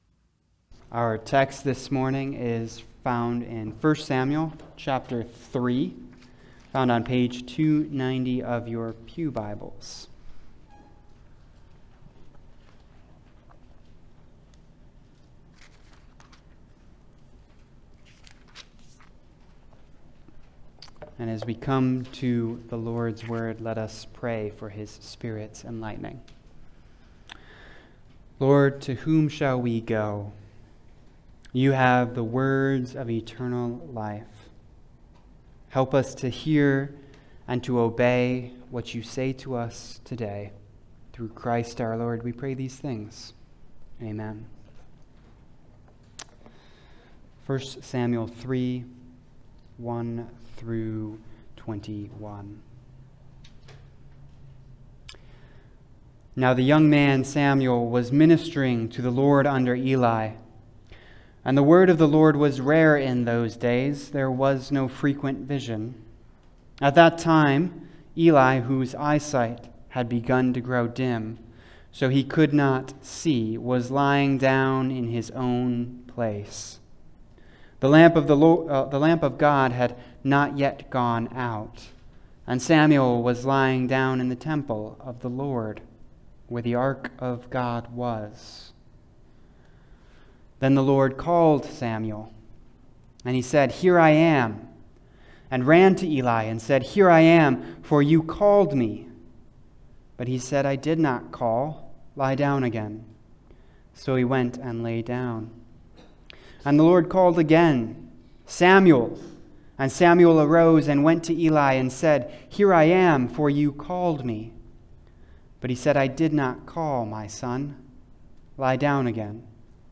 Passage: 1 Samuel 3:1-21 Service Type: Sunday Service « What’s Love Got to do With It?